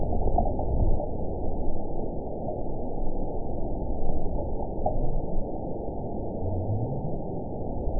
event 917170 date 03/23/23 time 00:41:08 GMT (2 years, 1 month ago) score 9.67 location TSS-AB03 detected by nrw target species NRW annotations +NRW Spectrogram: Frequency (kHz) vs. Time (s) audio not available .wav